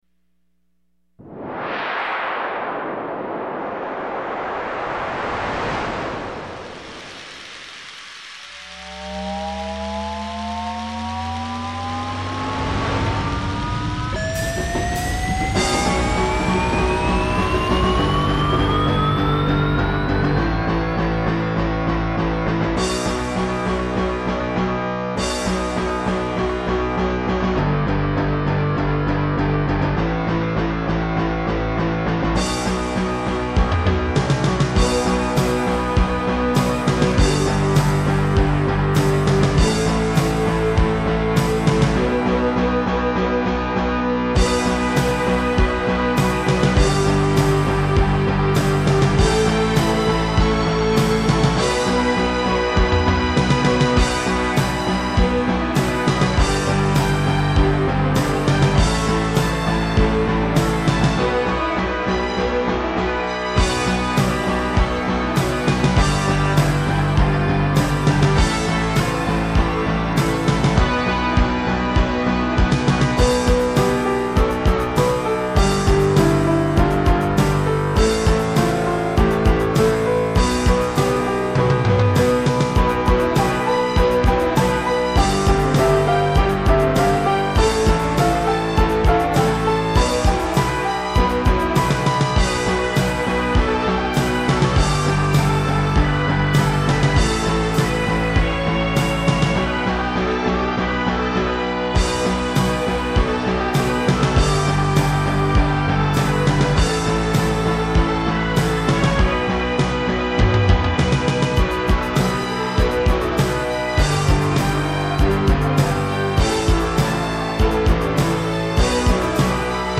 흐미 음악 너무 단조러워여..^^; 메탈곡을 만드려 보려했는데..